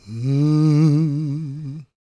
GOSPMALE001.wav